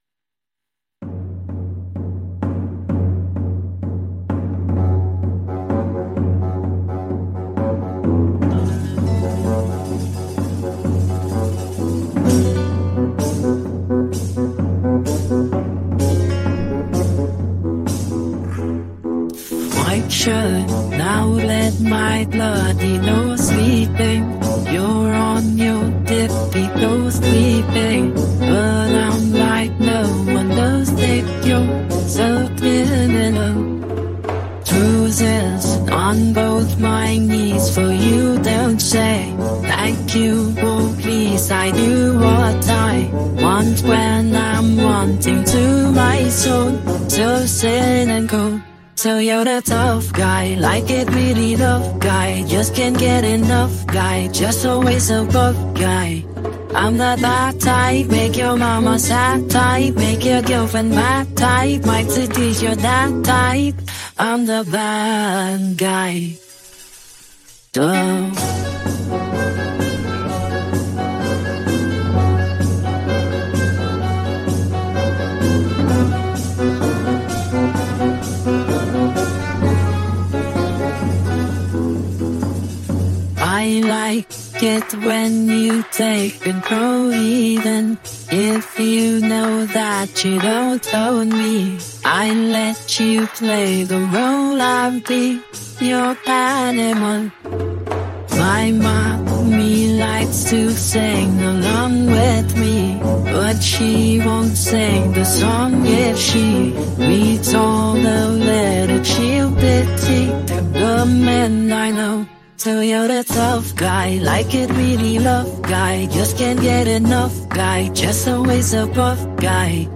tre brani "Bardcore" (in stile medioevale)
three "Bardcore" (medieval-style) songs